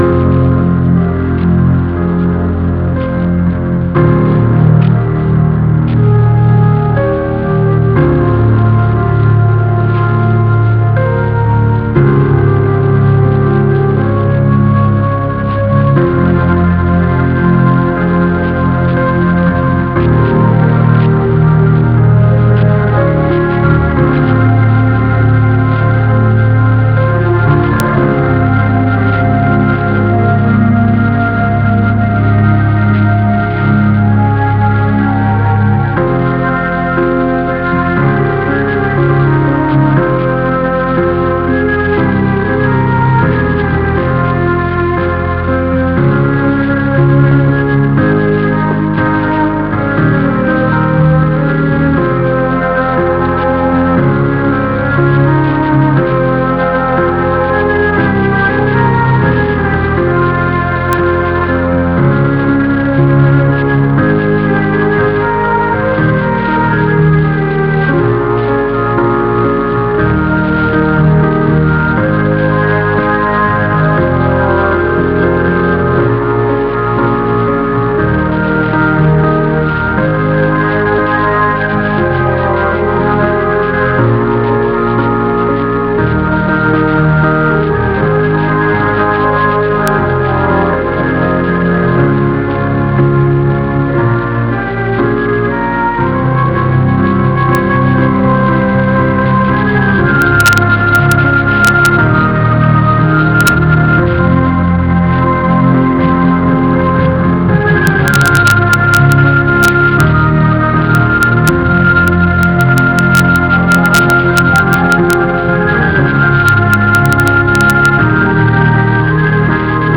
Прошу помочь с распознанием исполнителя мелодии.. извиняюсь за качество записи.